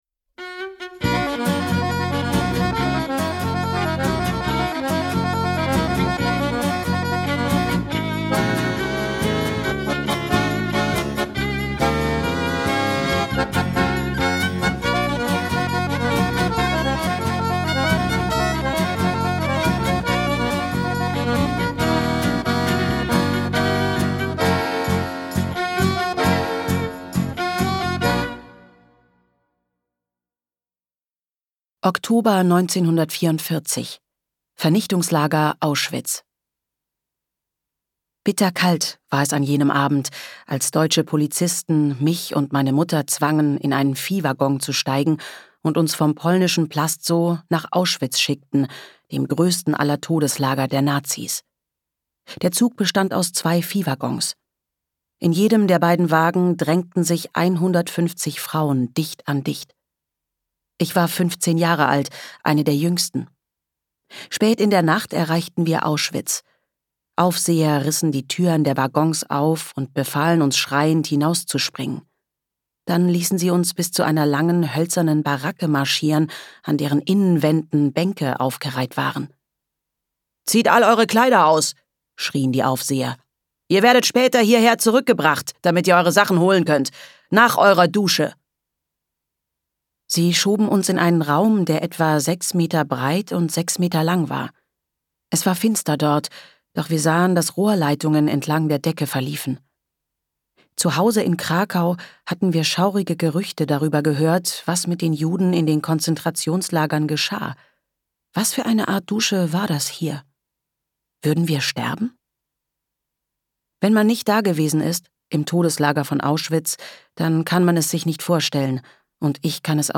Hörbuch für Kinder
mit Liedern